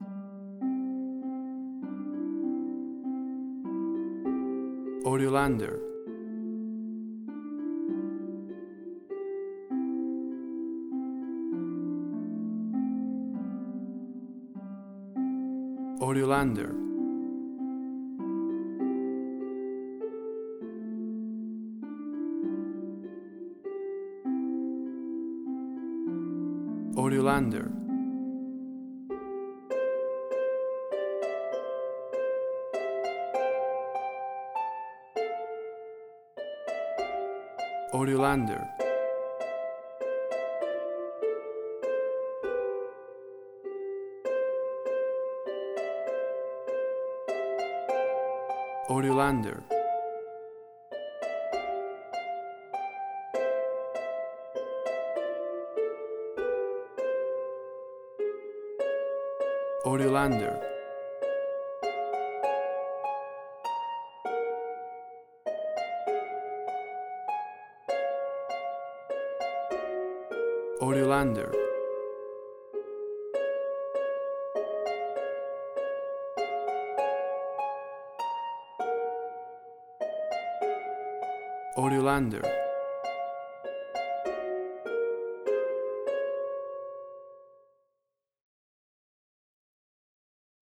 A traditional harp rendition
Full of happy joyful festive sounds and holiday feeling!.
WAV Sample Rate: 16-Bit stereo, 44.1 kHz
Tempo (BPM): 100